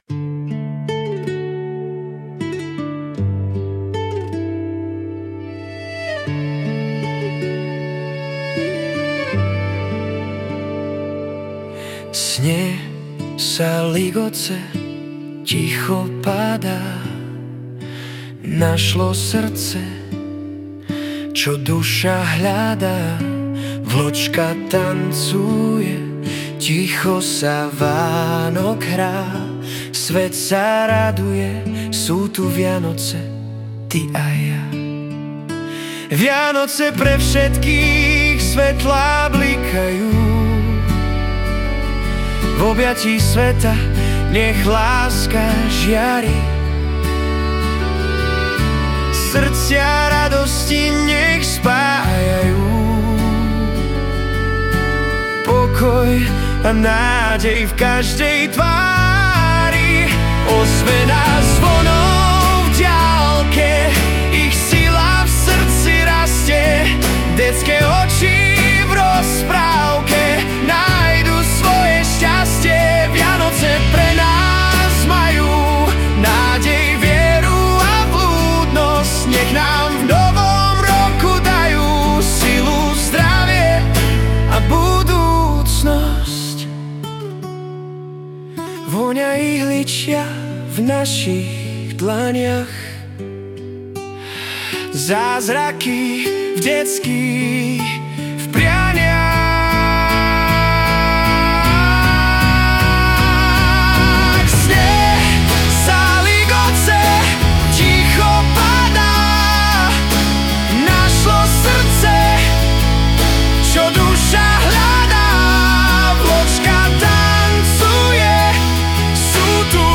Balady, romance » Romantické
Hudba a spev AI
energicky nazpívané... tak asi i AI umí aktivovat sílu